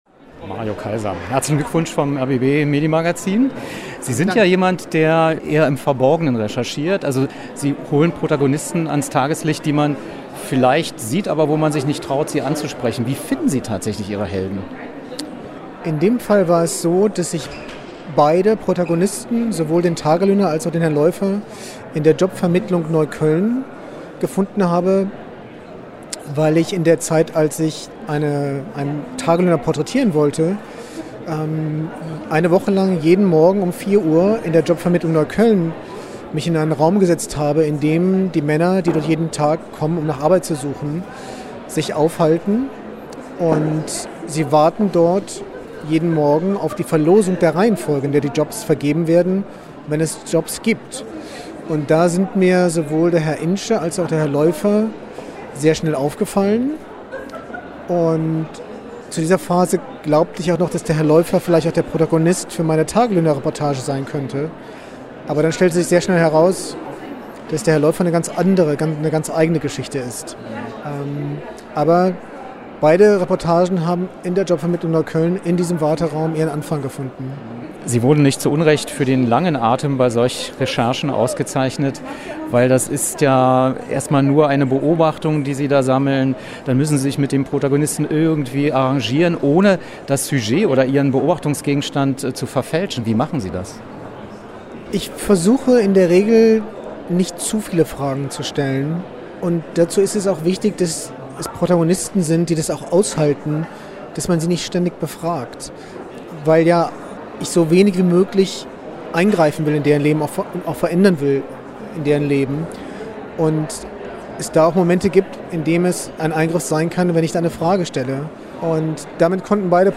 Was: Interview zum 2. Preis
Wo: Berlin, Akademie der Künste, Pariser Platz